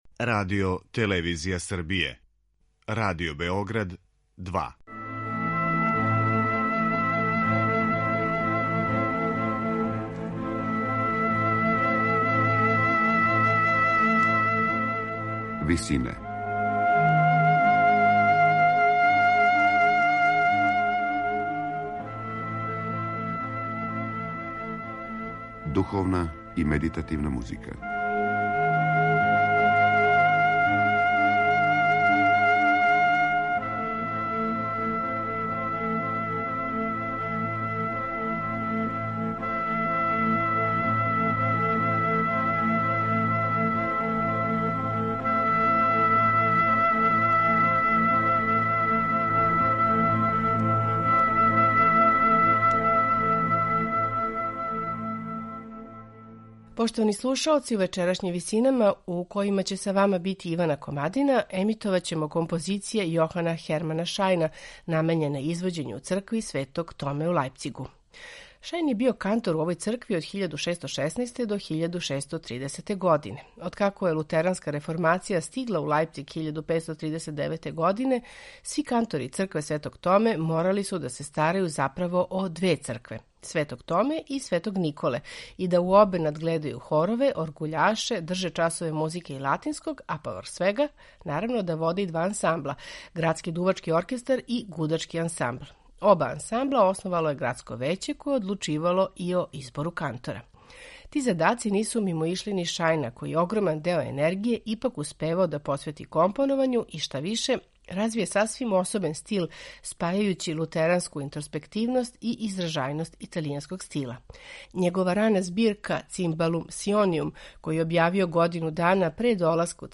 Духовна музика
Емитујемо Шајнове композиције писане за извођење у цркви Светог Томе: канцоне, свите и обраде протестанских корала.
сопрани
тенор и ансамбл Иналто.